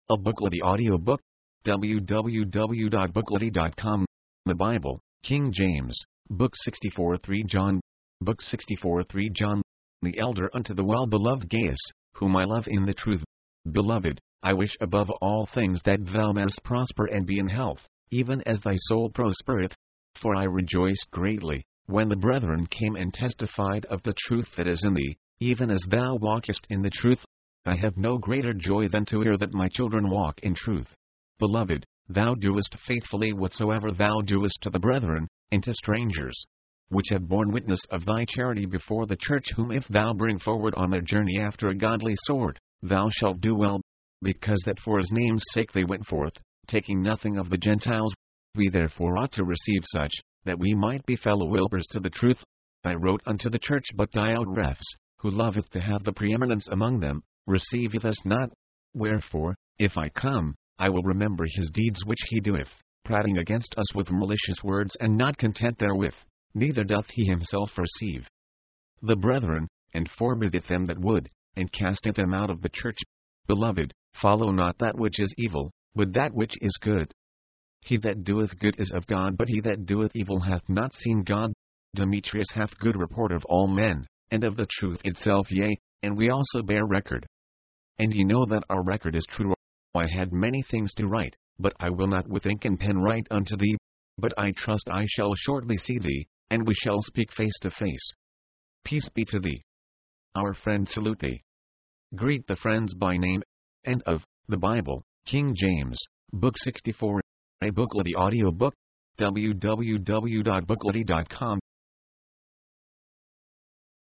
The author of the letter requests that support be given to the travelers. mp3, audiobook, audio, book